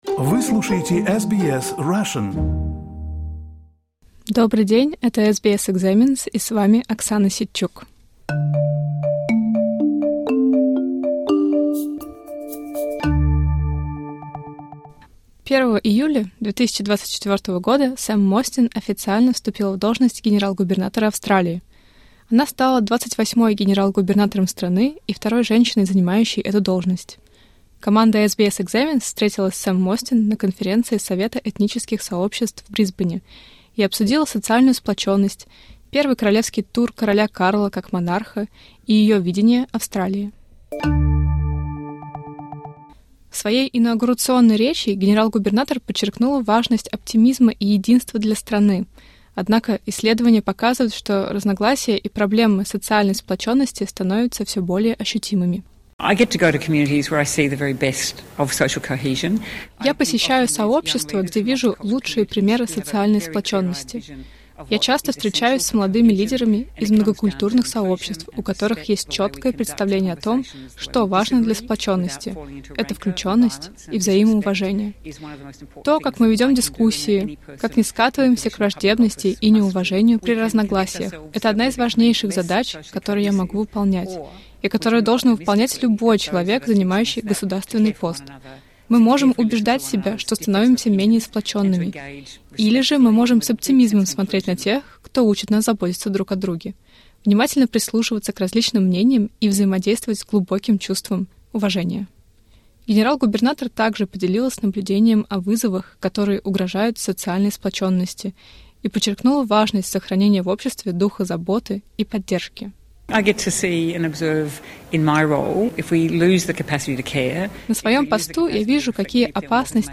В интервью для SBS Examines Сэм Мостин поделилась своими мыслями о социальной сплоченности, оптимизме и роли монархии.